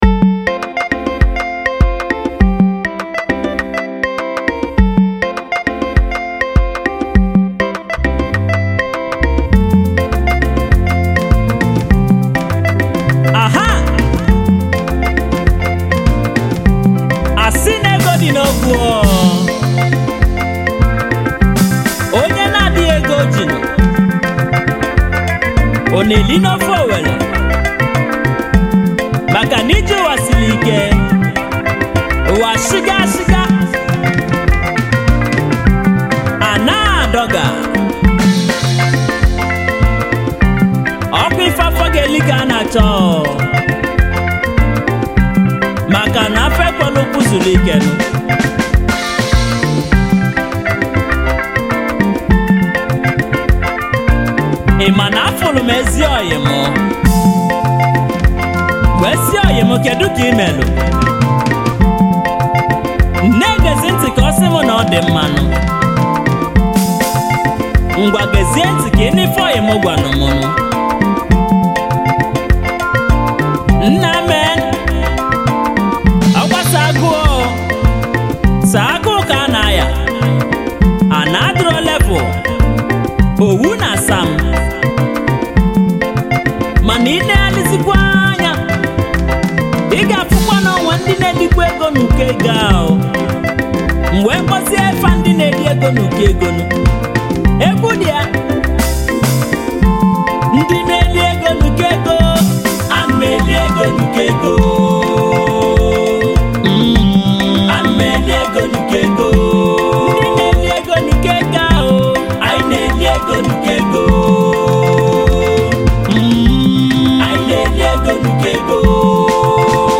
His melody and harmony is straight to the soul.